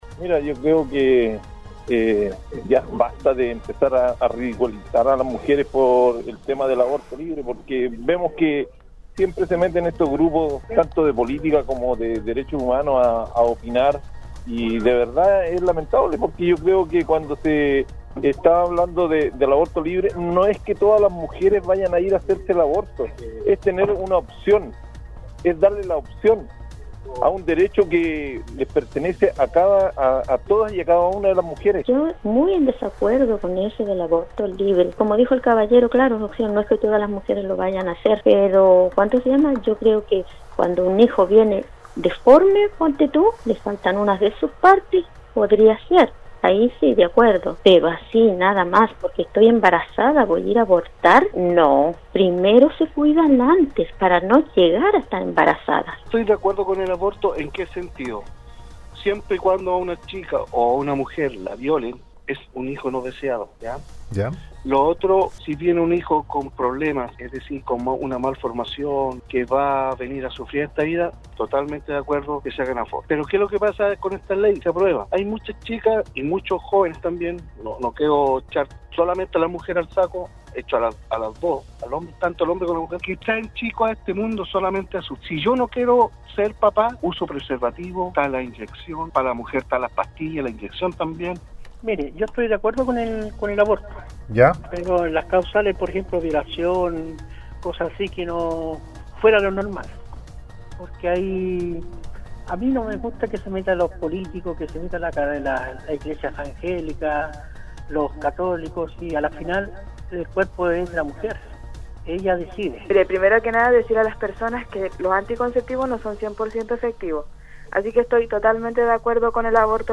La mañana de este viernes, auditores y auditoras de Nostálgica participaron en el foro del programa Al día, donde entregaron su distintos puntos de vista con respecto a la séptima marcha del Movimiento Feminista realizada en Santiago y otras ciudades del país, donde se busca que el aborto sea libre, legal, seguro y gratuito en Chile.
Al respecto, se recibieron muchos mensajes y llamadas criticando esta posición que para muchos irían en contra de sus propias creencias.